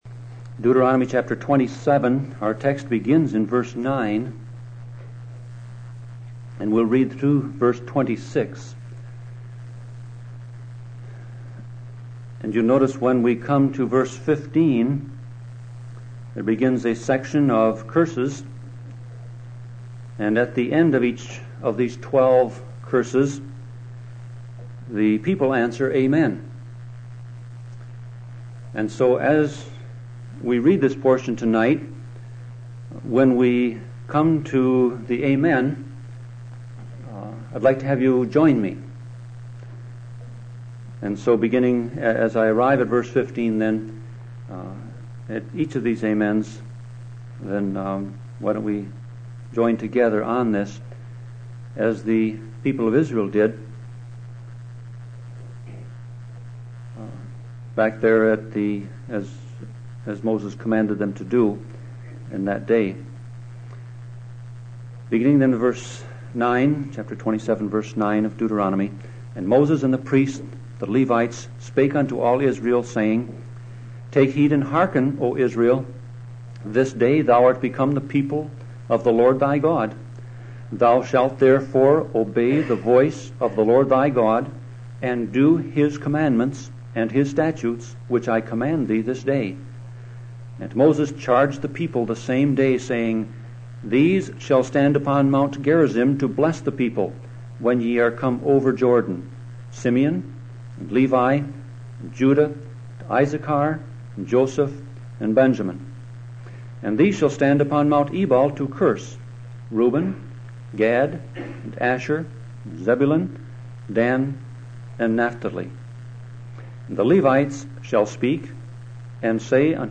Sermon Audio Passage: Deuteronomy 27:9-26 Service Type